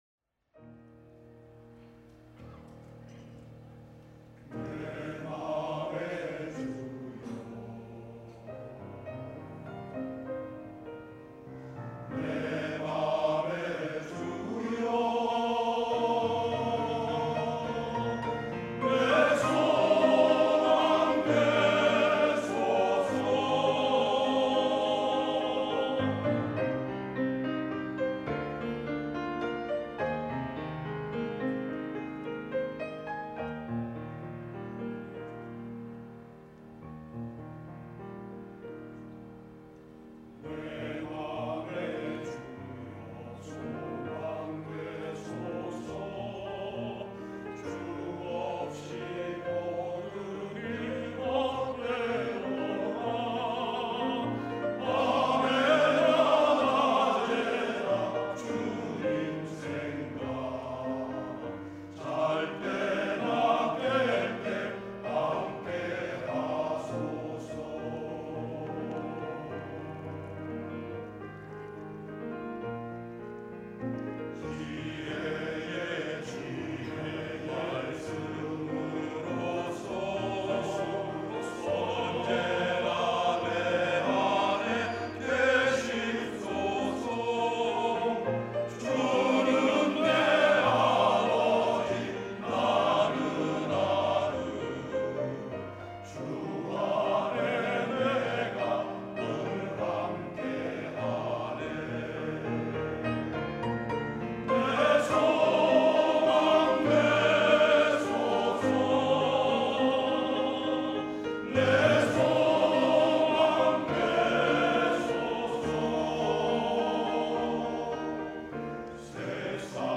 # 첨부 1.02 내 맘의 주여 소망되소서(갈렐남성합창단).mp3